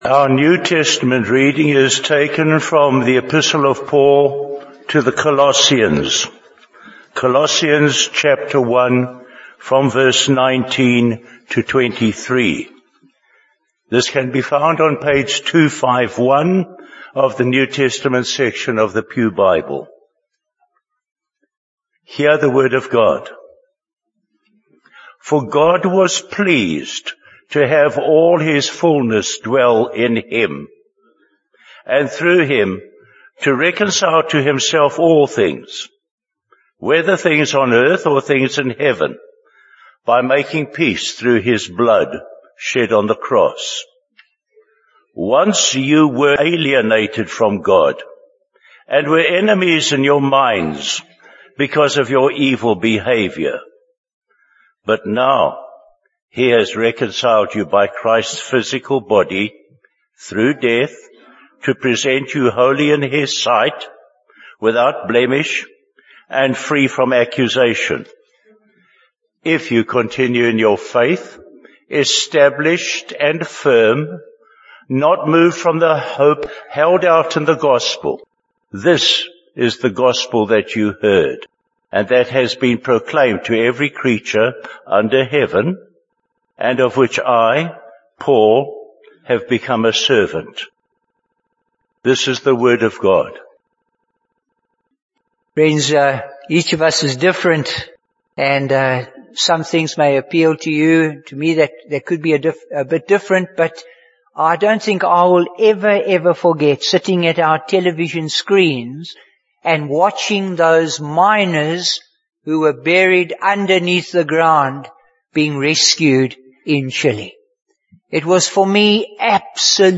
Bible Text: Colossians 1: 19-23 | Preacher: Bishop Warwick Cole-Edwards | Series: Colossians